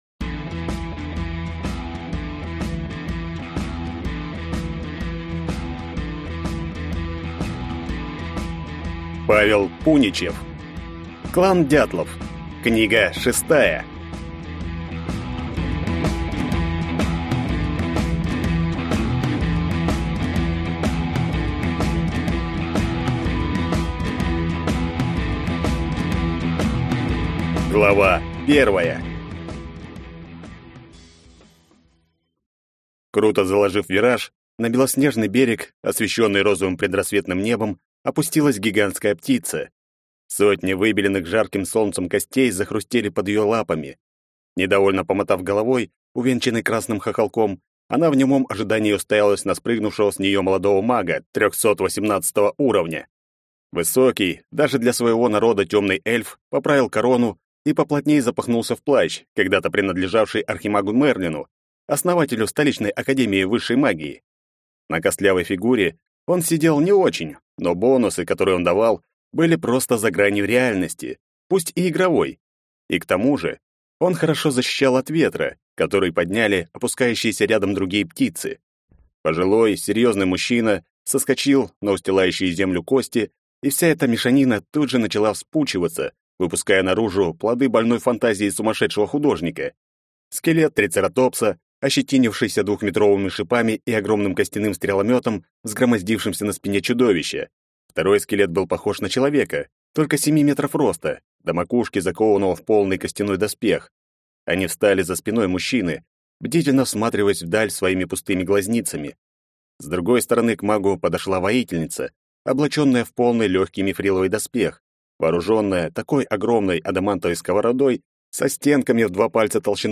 Aудиокнига Клан «Дятлов».